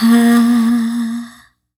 Female Vox-A#.wav